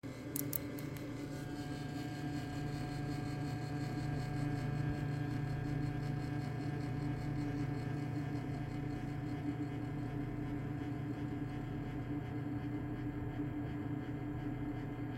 Nowa karta graficzna i bzyczenie (głośny dźwięk)
Te trzaski to prawdopodobnie cewka. Jednak na moje ucho wentylatory też działają fatalnie.
No wentylatory nie brzmią zachęcająco jak i te trzaski przy uruchamianiu.